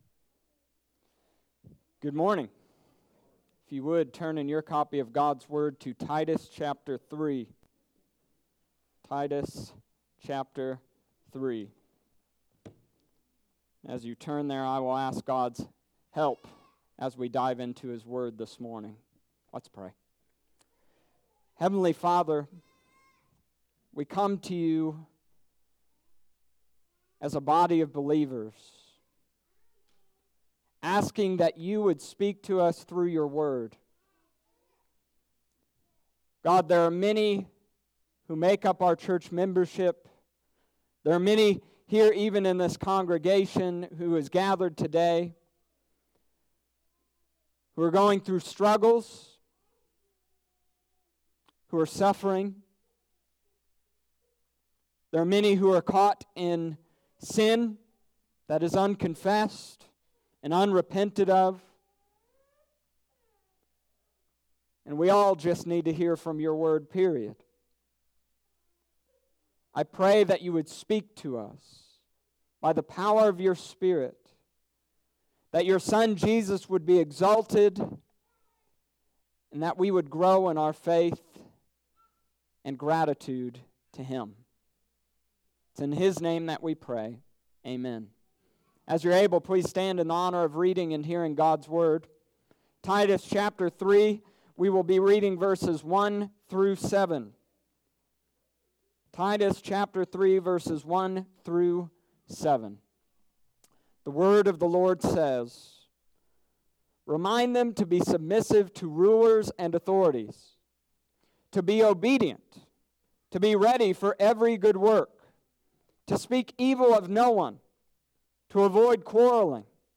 Summary of Sermon: This week, we continued the book of Titus.